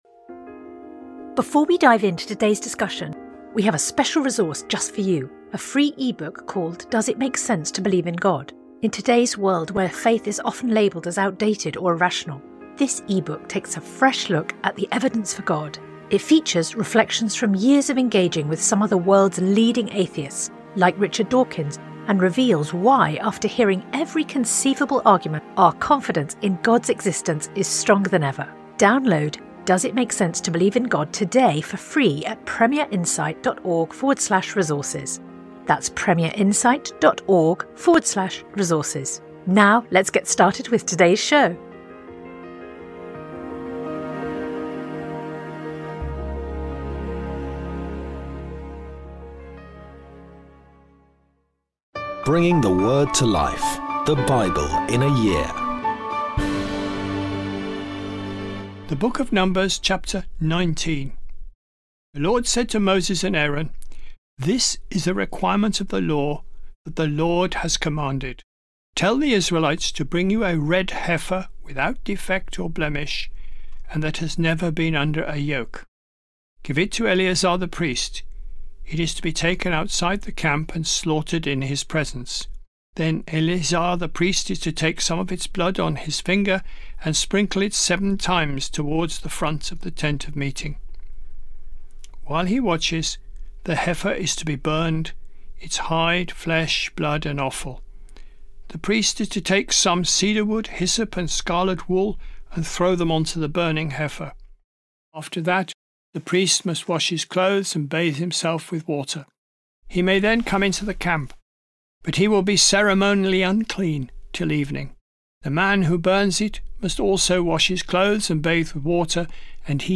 Today's readings come from Numbers 19-20; Psalms 90; John 2